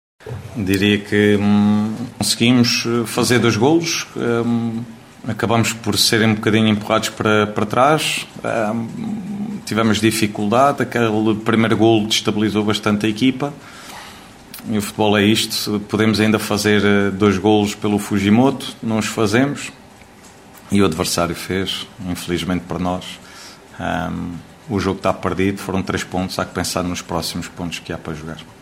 No final do desafio, Bruno Pinheiro, treinador dos gilistas, defendeu que o golo sofrido “abalou a equipa”.